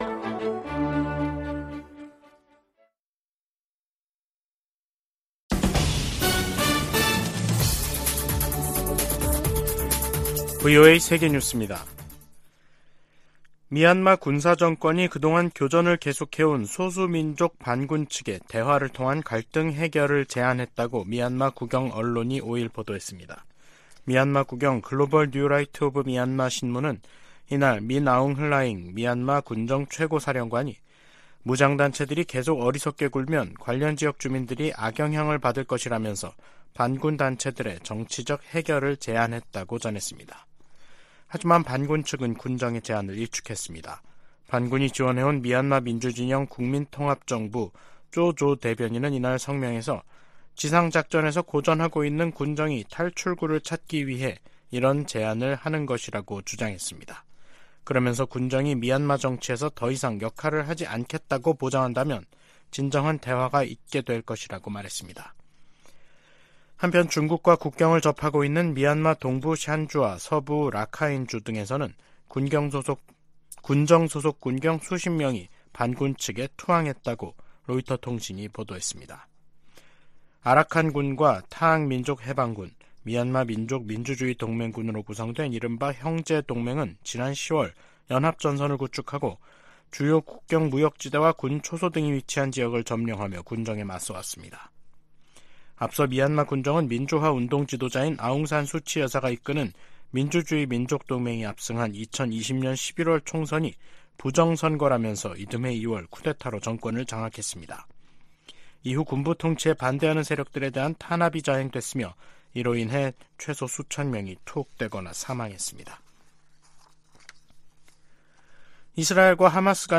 세계 뉴스와 함께 미국의 모든 것을 소개하는 '생방송 여기는 워싱턴입니다', 2023년 12월 5일 저녁 방송입니다. '지구촌 오늘'에서는 러시아와 싸우는 우크라이나를 도울 시간과 돈이 바닥나고 있다고 백악관이 경고한 소식 전해드리고, '아메리카 나우'에서는 민주당 주지사들이 조 바이든 대통령에게 낙태권리 등과 정책·입법 성과 홍보를 강화해야 한다고 조언한 이야기 살펴보겠습니다.